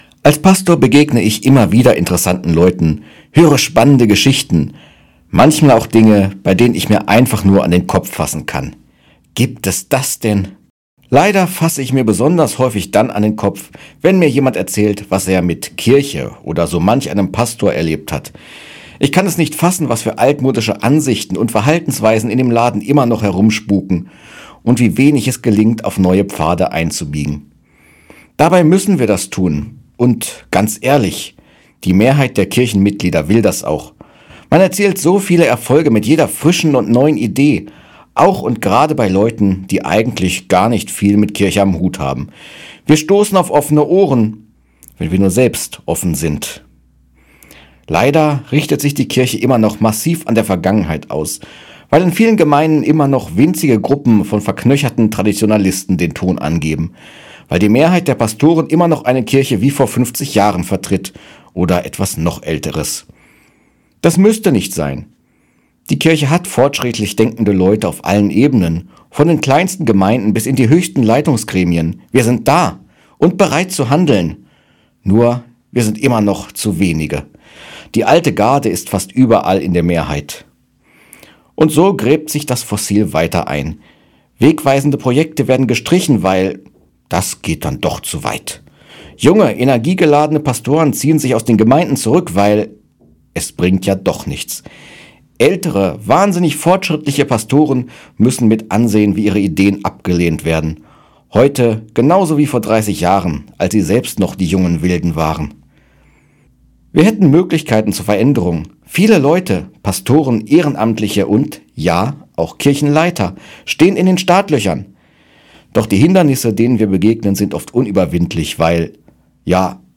Radioandacht vom 27. November